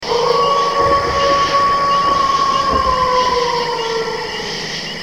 Download Halloween Scary sound effect for free.
Halloween Scary